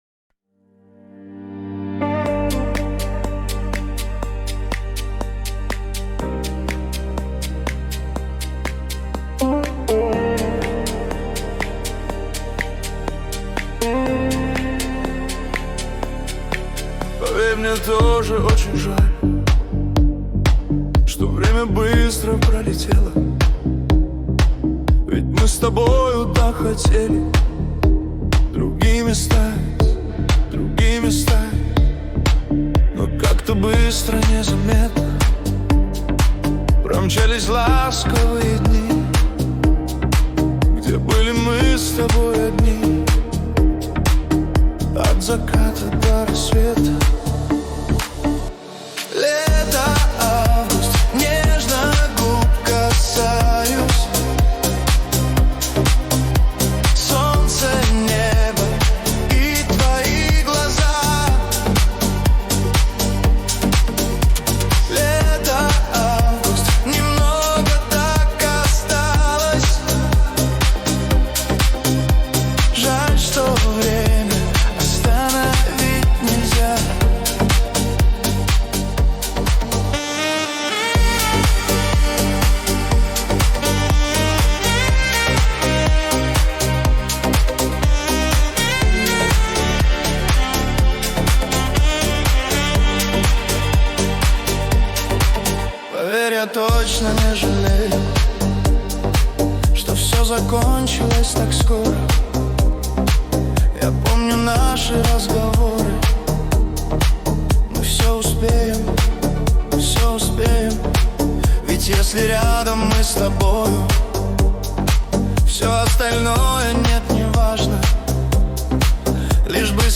Качество: 320 kbps, stereo
Поп музыка, Тренды 2025, Тик Ток